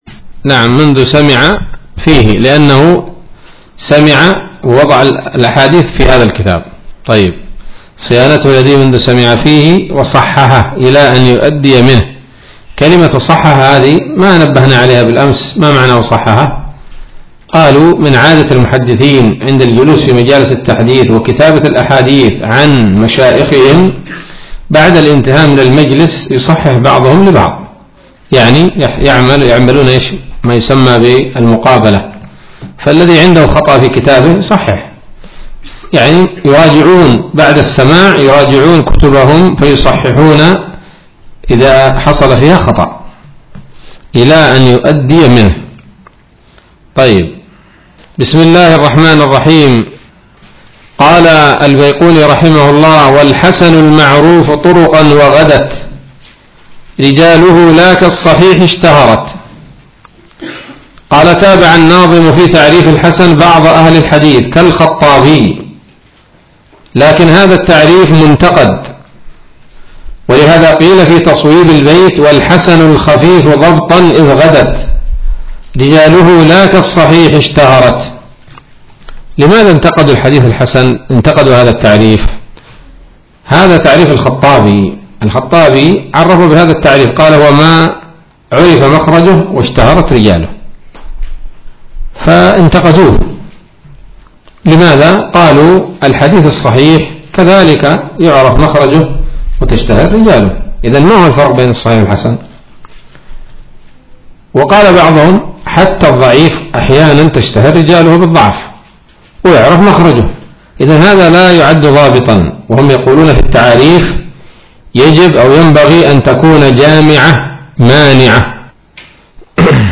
الدرس السابع من الفتوحات القيومية في شرح البيقونية [1444هـ]